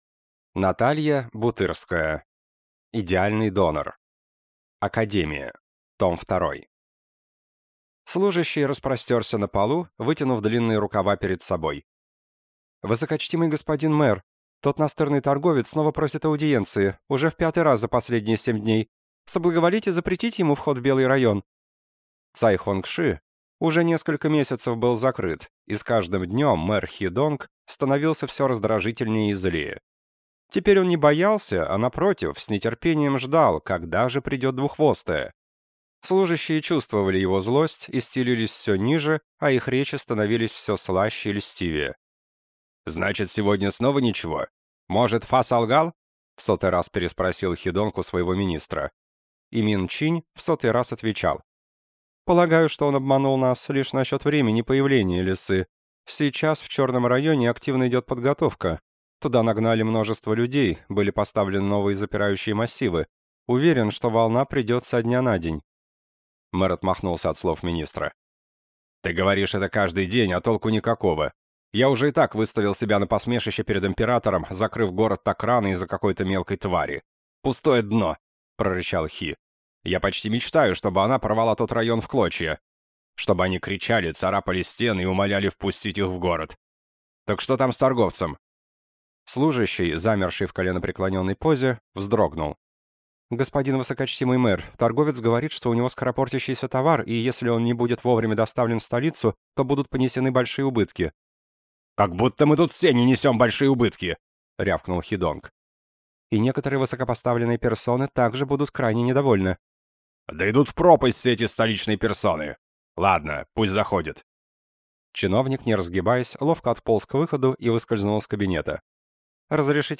Аудиокнига Идеальный донор. Академия. Книга 2 | Библиотека аудиокниг